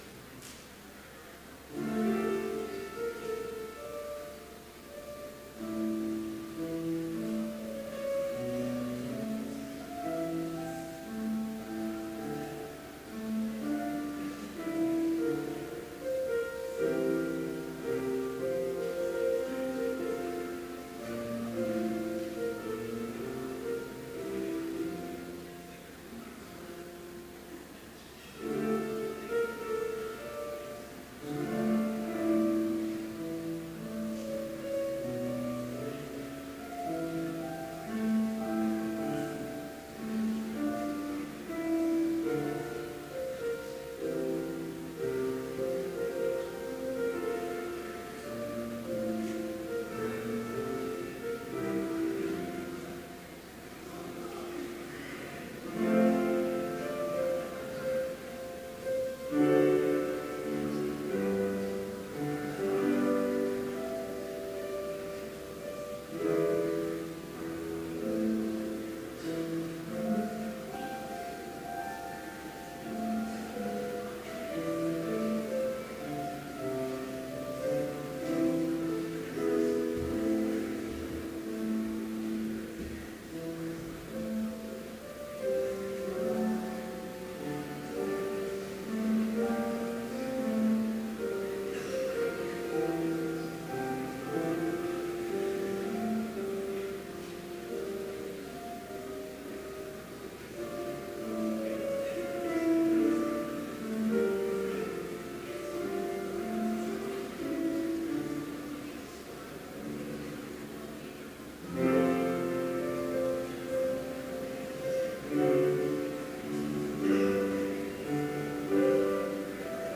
Chapel worship service held on April 16, 2018, BLC Trinity Chapel, Mankato, Minnesota,
Complete service audio for Chapel - April 16, 2018